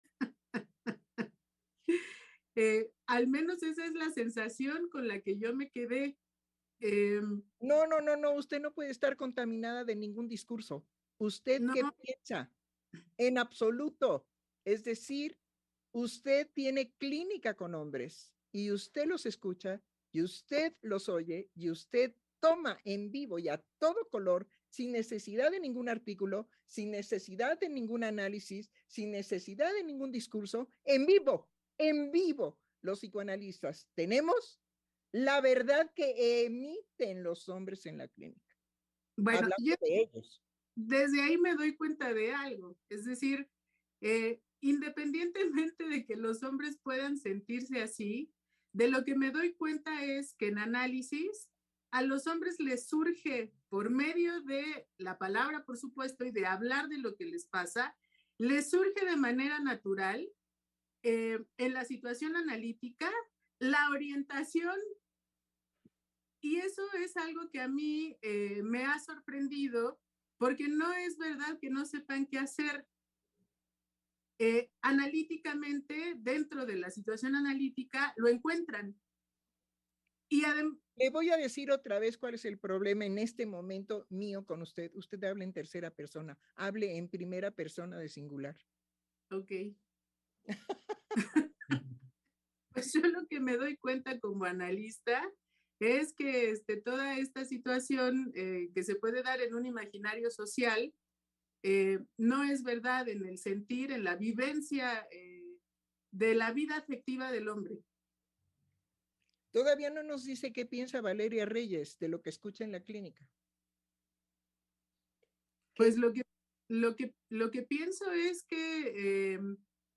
Programa transmitido el 23 de noviembre del 2022.